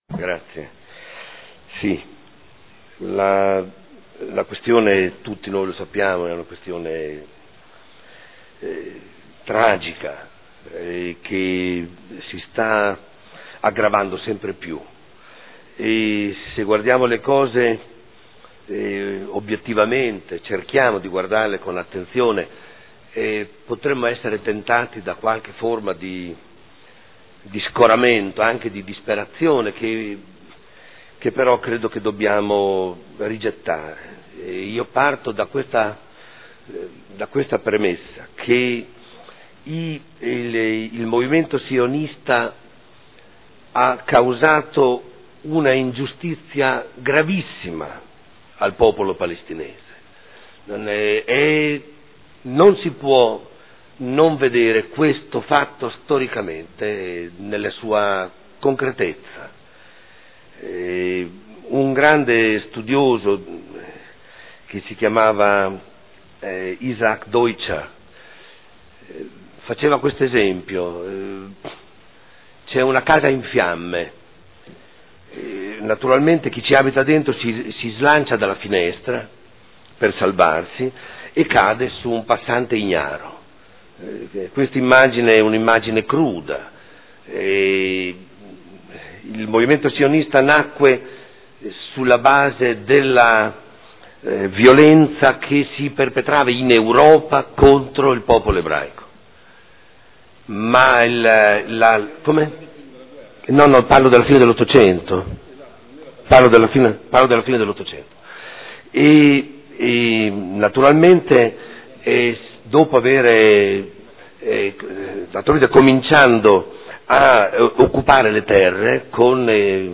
Seduta del 21/05/2015 dibattito mozioni 27236 e 58705 sulla Palestina.